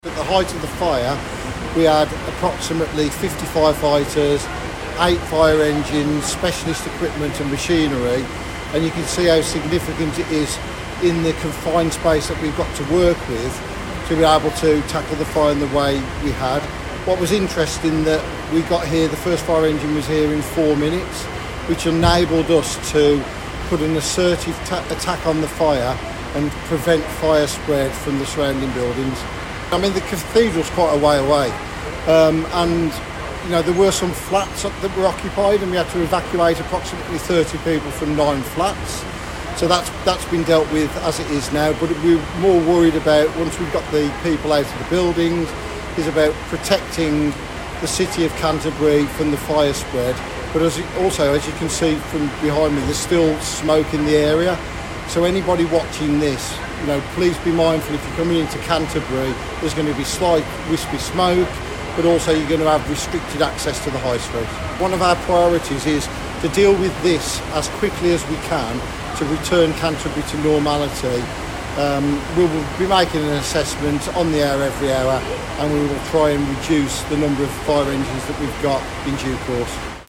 at the scene of a blaze on Canterbury High Street